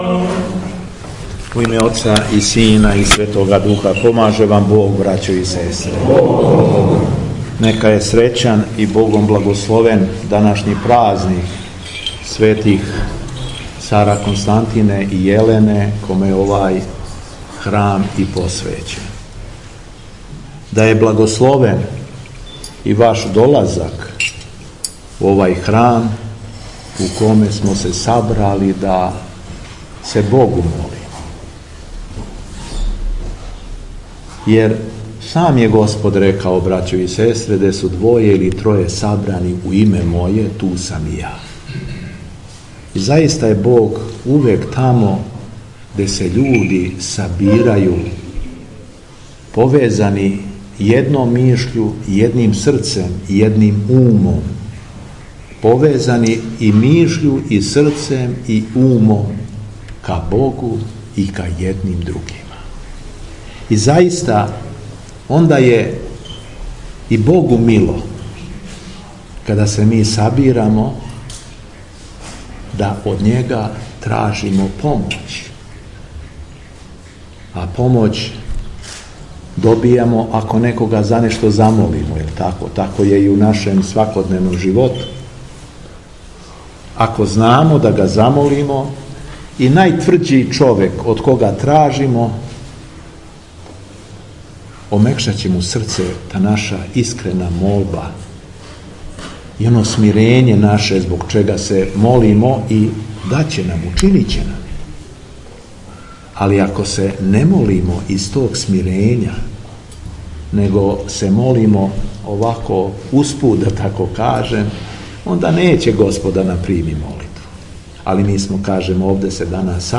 У среду, 3. јуна 2020. године, када наша Света Црква прославља и празнује Свете равноапостолне цареве Константина и Јелену, Његово Преосвештенство Епископ шумадијски Господин Јован служио је Свету архијерејску Литургију у Опарићу поводом славе храма.
Беседа Његовог Преосвештенства Епископа шумадијског Г. Јована